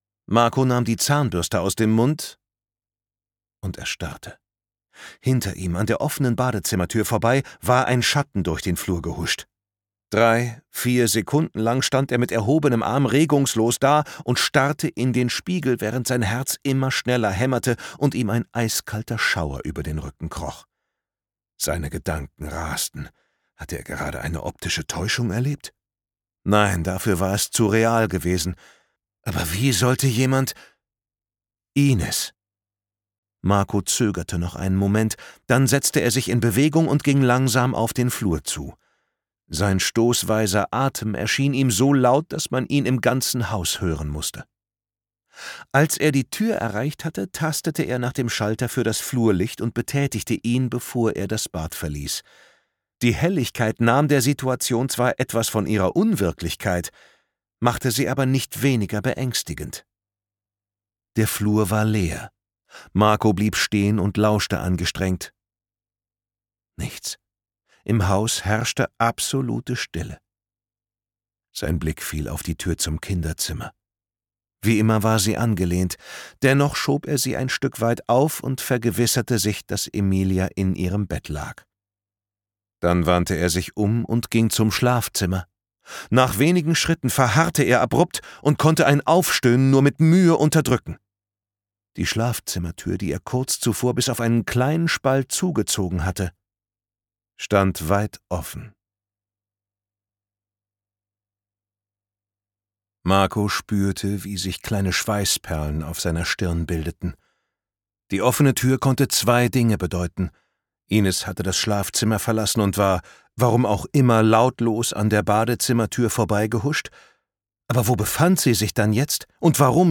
Sascha Rotermund überzeugt durch seine lebendige Figurengestaltung, seine mitreißenden Dialoge und seine warme, dennoch spannungsgeladene Stimme.
Gekürzt Autorisierte, d.h. von Autor:innen und / oder Verlagen freigegebene, bearbeitete Fassung.
Welcome Home – Du liebst dein neues Zuhause. Hier bist du sicher. Oder? Gelesen von: Sascha Rotermund